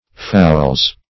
Fowl \Fowl\ (foul), n.